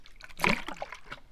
slosh2.wav